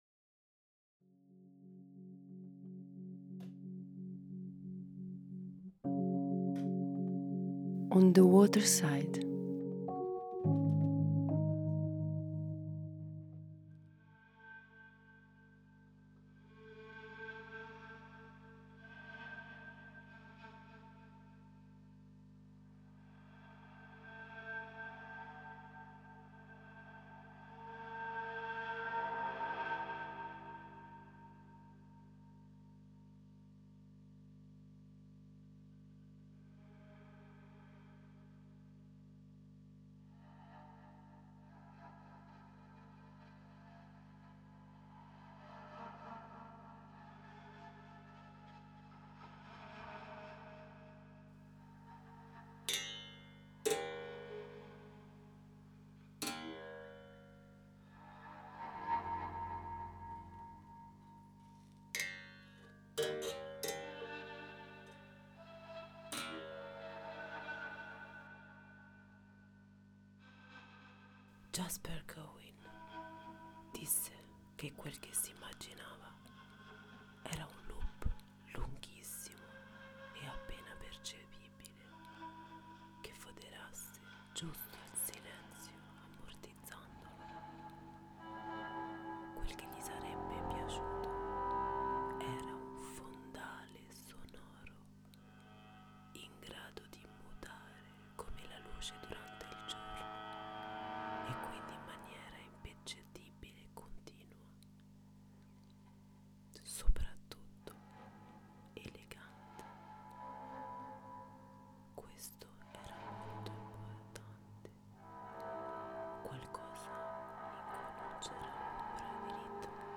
La portion court de la poésie sonore où tout se confonde. Amalgame de la lecture des fragments des ouvres en langue d’origine / Interprétation des écrits étranger différent, inexploré. Exploration de les sons dirty et les mélangeant avec des mélodies harmoniques. Chaque portion vous offre le son de la langue etranger superposés sur un tapis sonore crée exprès pour approcher l’ambiance. e